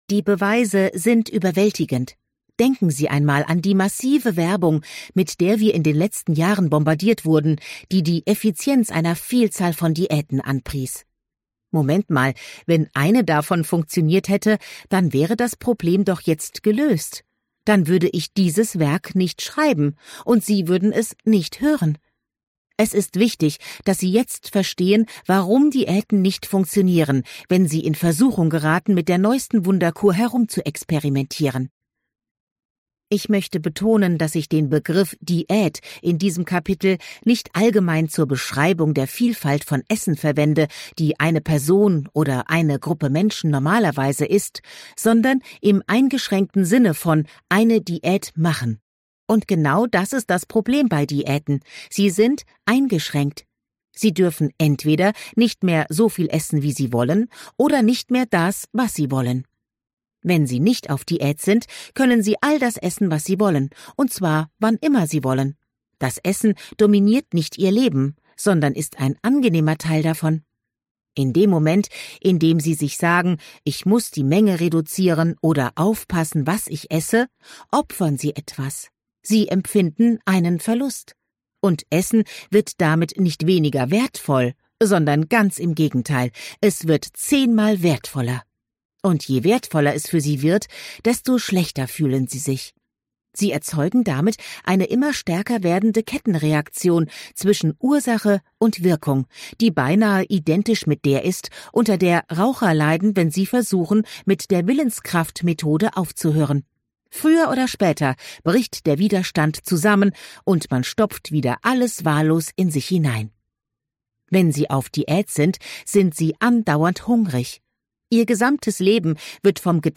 2026 | Ungekürzte Lesung